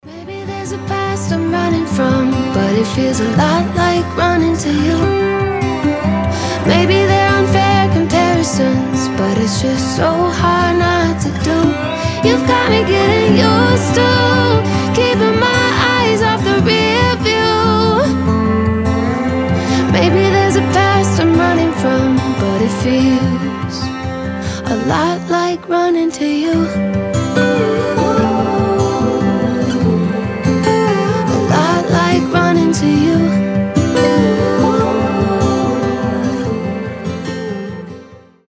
• Качество: 320, Stereo
спокойные
красивый женский голос